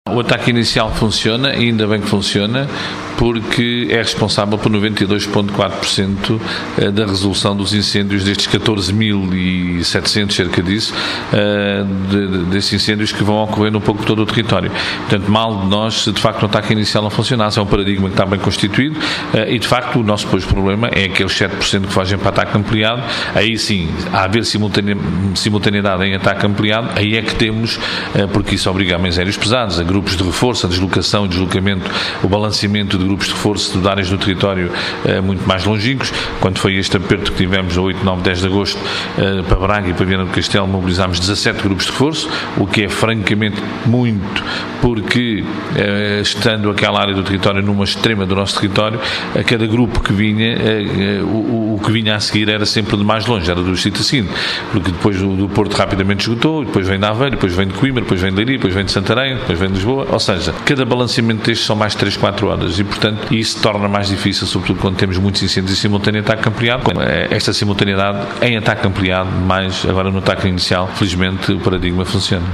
O Comandante Nacional de Protecção Civil em entrevista exclusiva à CIR referiu que o ataque inicial funciona travando assim a propagação do fogo e evitando o ataque ampliado